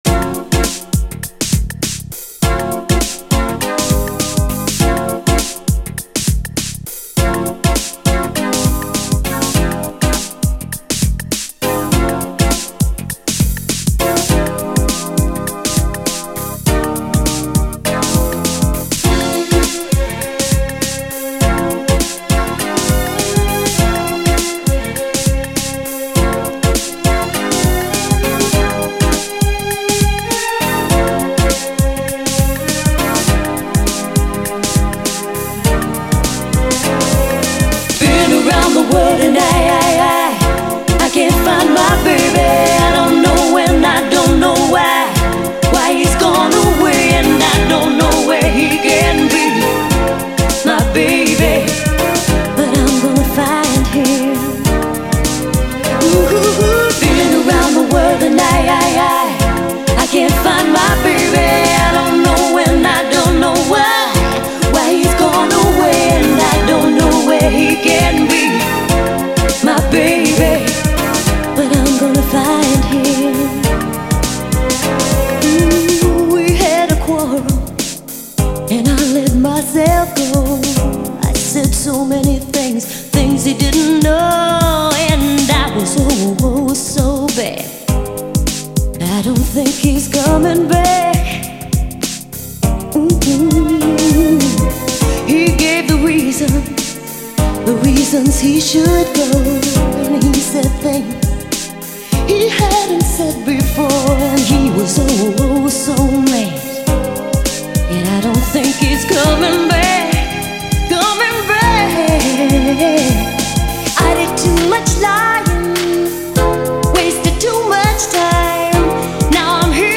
SOUL, 70's～ SOUL, DANCE
89年大ヒット・UKソウル〜グラウンド・ビート！
流麗＆キャッチー＆ソウルフルな大名曲